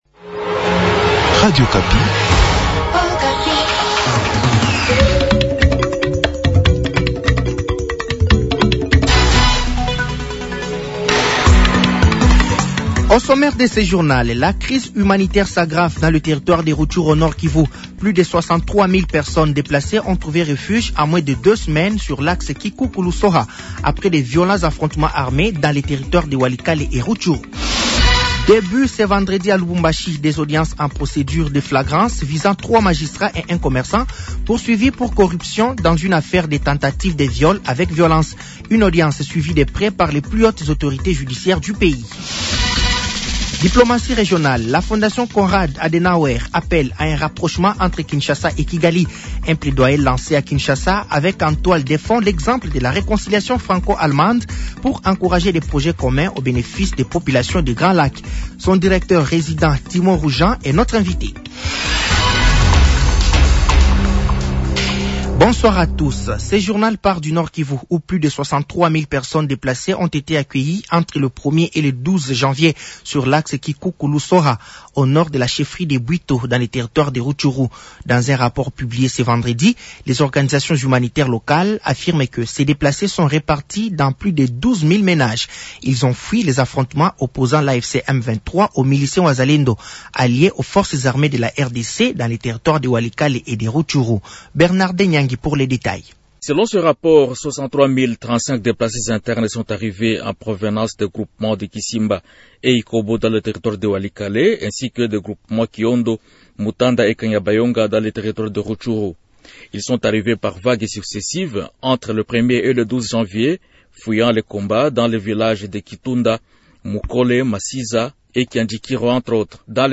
Journal français de 18h de ce samedi 24 janvier 2026